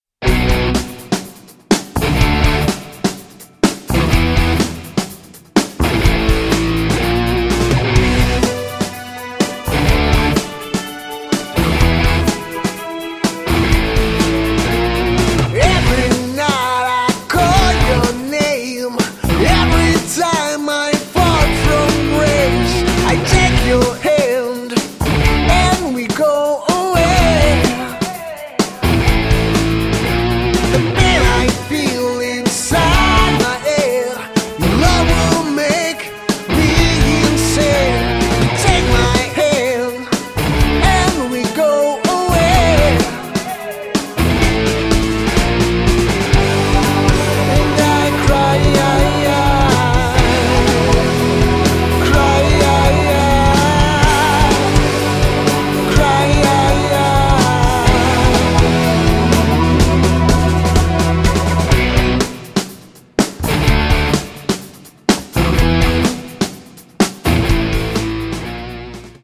Enregistrement Studio Taurus (GE)